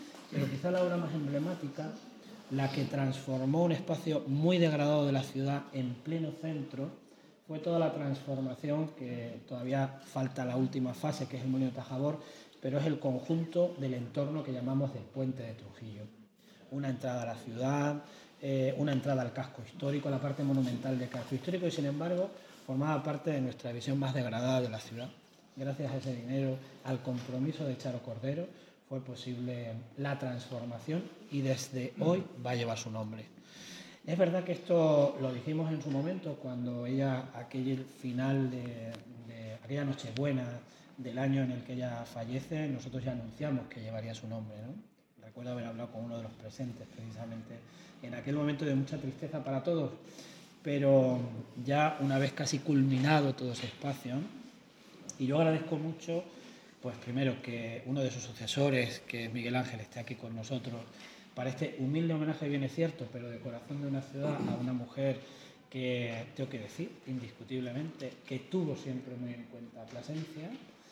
En un sencillo pero emotivo acto celebrado en el Salón Noble del Ayuntamiento de Plasencia se ha hecho entrega a la familia de Charo Cordero, presidenta de la Diputación de Cáceres, una copia del azulejo que se instalará en los próximos días en el entorno de la Puerta de Trujillo, concretamente en la parte superior de los aparcamientos públicos de la Calle Eulogio González, espacio que pasará a denominarse “Plaza María del Rosario Cordero Martín”.
CORTES DE VOZ